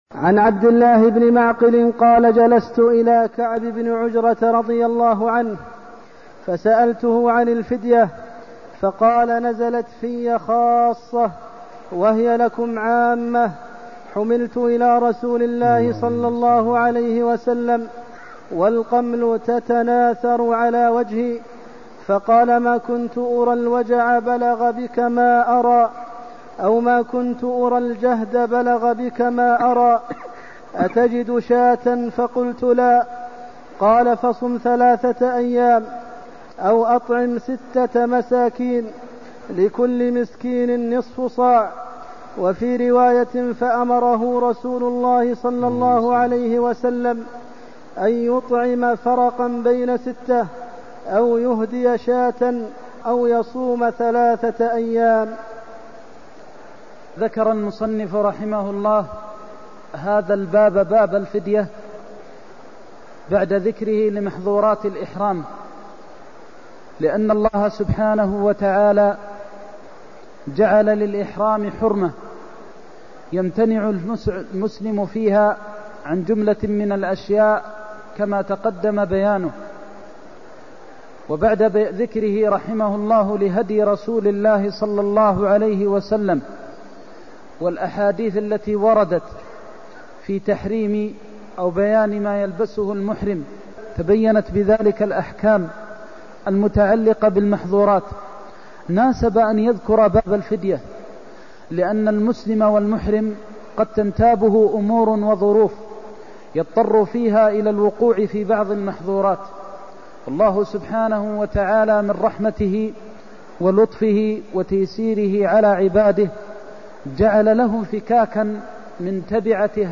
المكان: المسجد النبوي الشيخ: فضيلة الشيخ د. محمد بن محمد المختار فضيلة الشيخ د. محمد بن محمد المختار حملت إلى النبي والقمل يتناثر على وجهي (208)